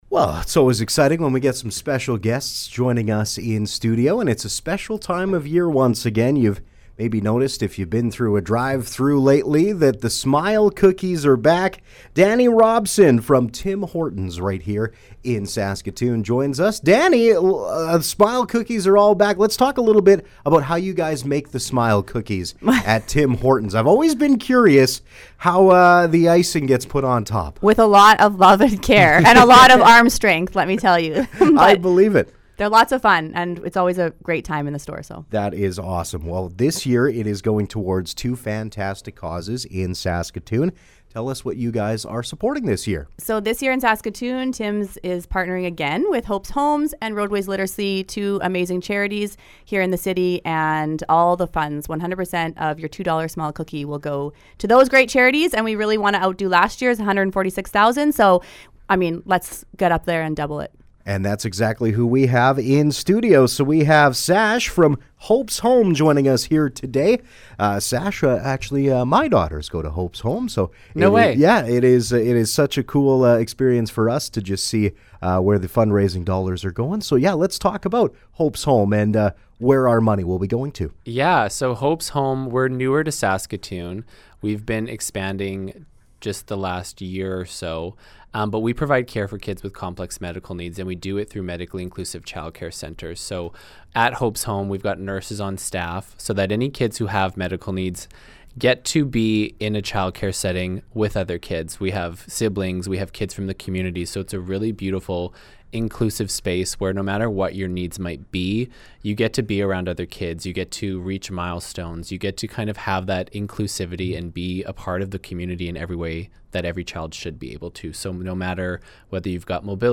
Interview: Smile Cookies
smile-cookie-interview-2025.mp3